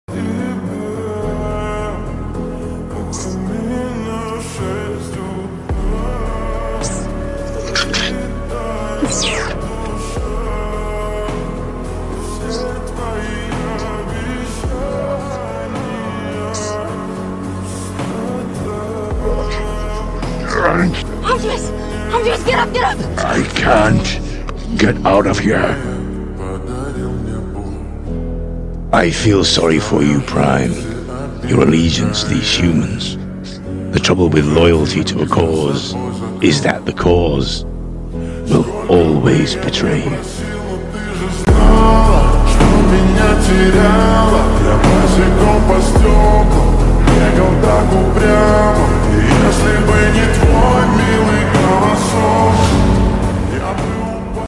Slowed Reverb